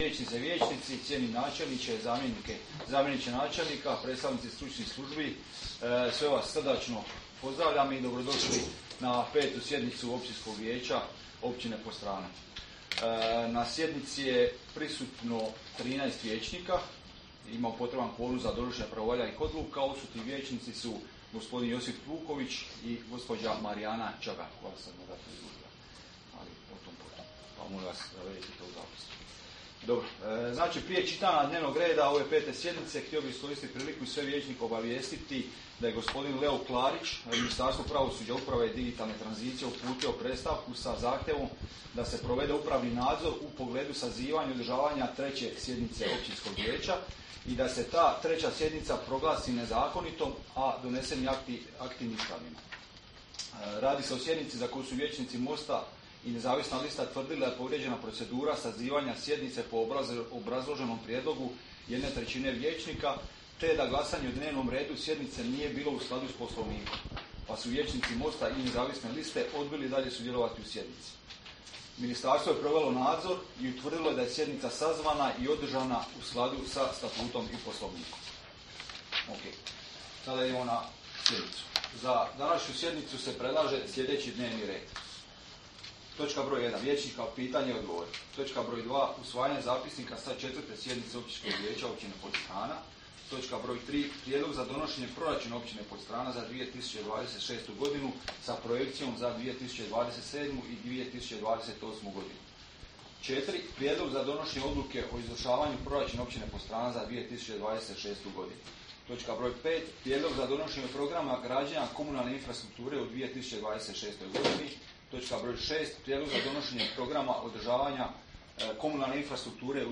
Sjednica će se održati dana 03. prosinca (srijeda) 2025. godine u 19,00 sati u Vijećnici Općine Podstrana.